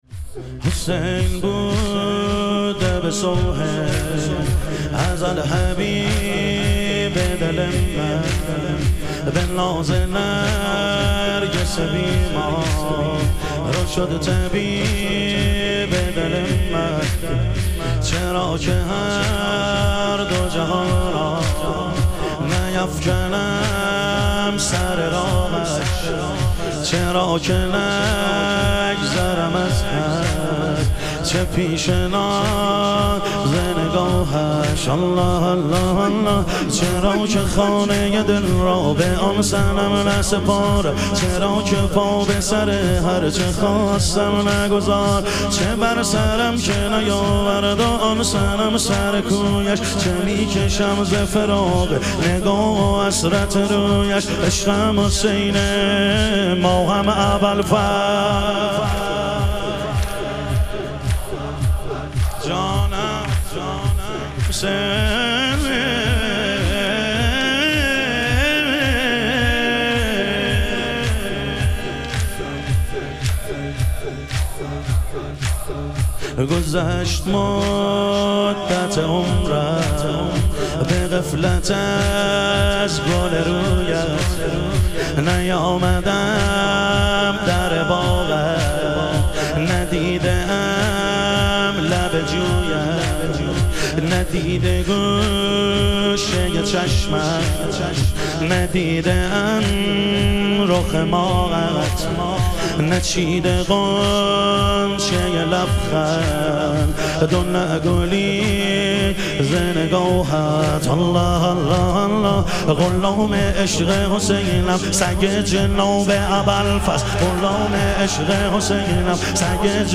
شب چاوش محرم